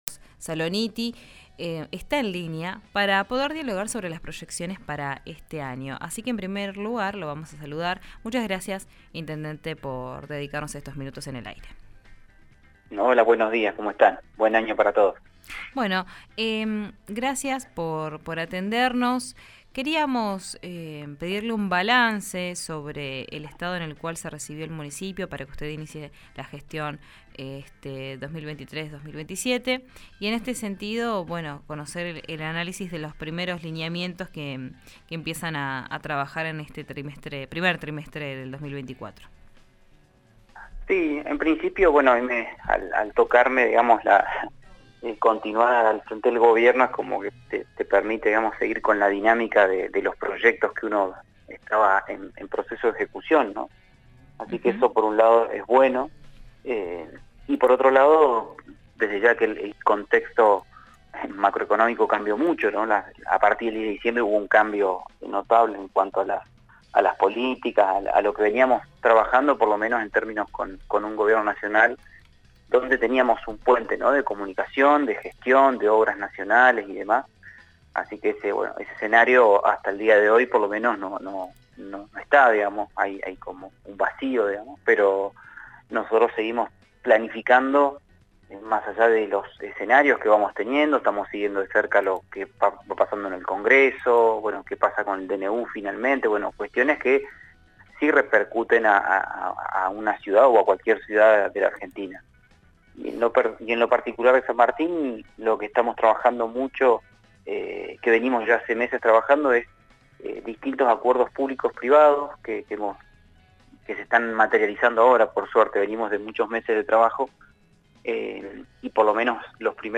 Así lo aseguró el jefe comunal de San Martín de los Andes en diálogo con RÍO NEGRO RADIO, tras el encuentro con sus pares. Escuchá la entrevista completa.